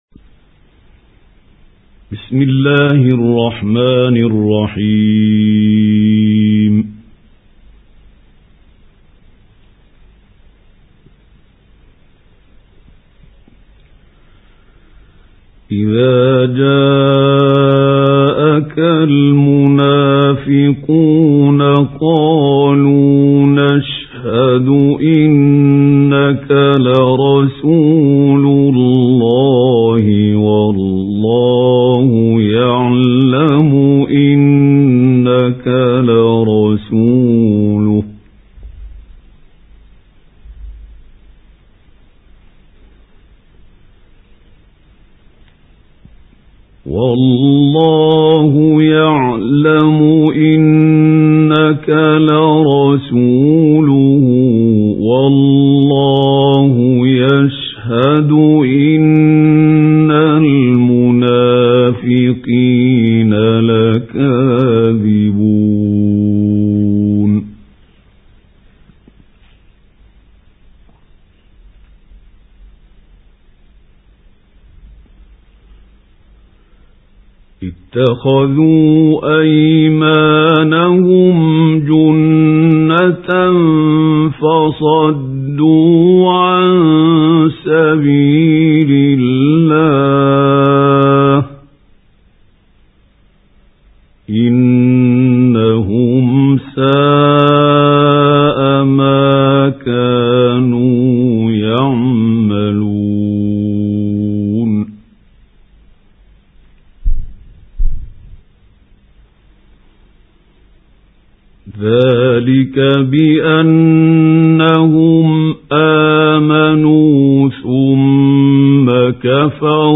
سُورَةُ المُنَافِقُونَ بصوت الشيخ محمود خليل الحصري